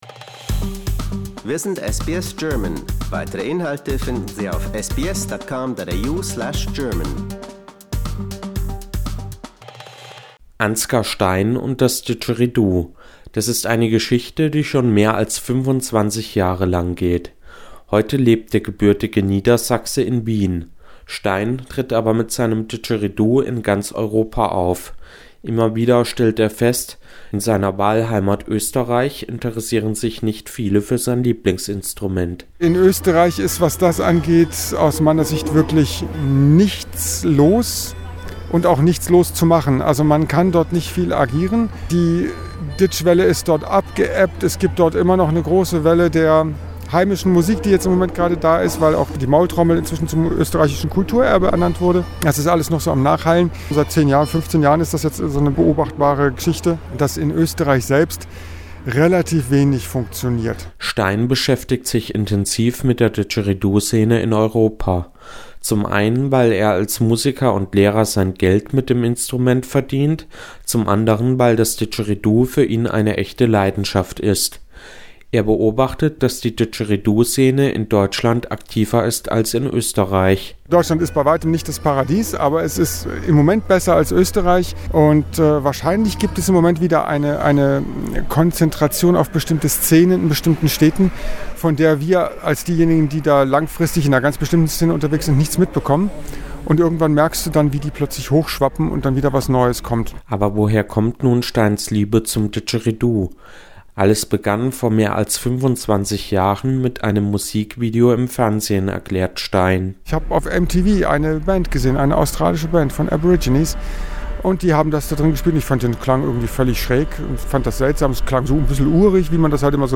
He usually performs all around Europe, playing the didgeridoo.
modernes Didgeridoo-Spiel und traditionelle Einflüsse zu einem elektronikfreien Trance-Programm